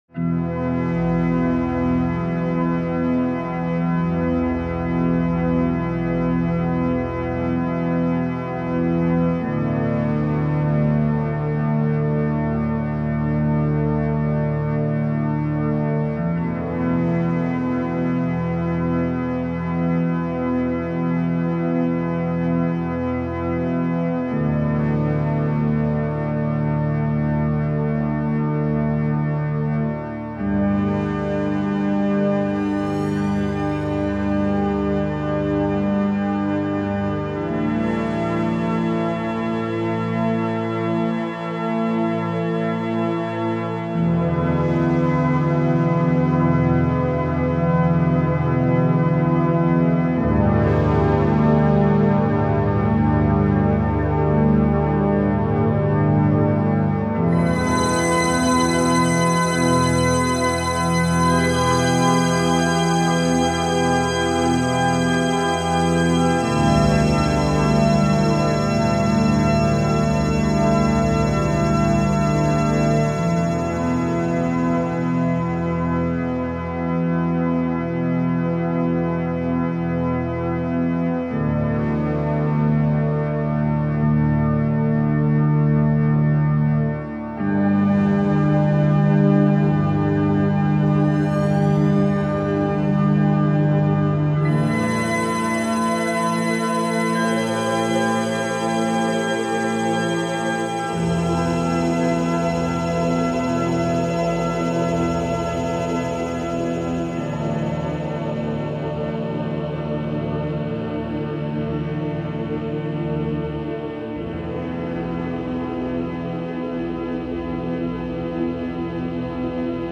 3 x church organs, fx.